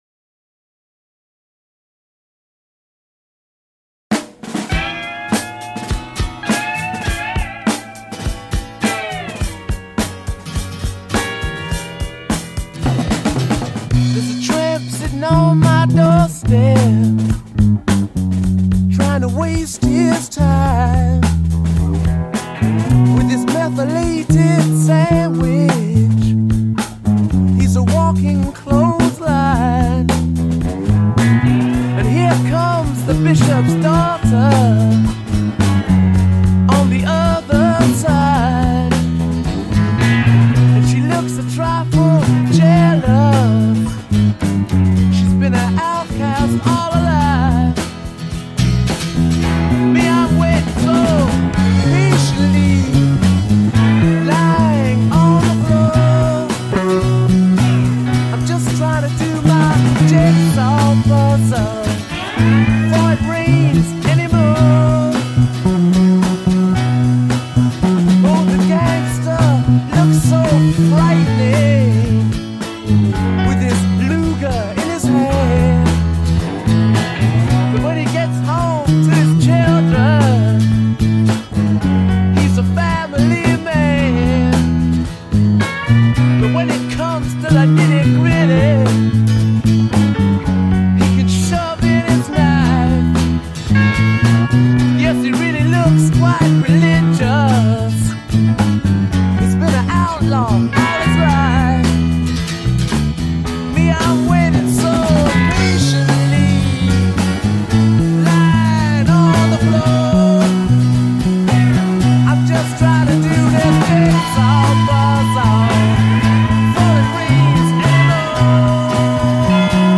c’est une sorte de mélopée blues
slide guitar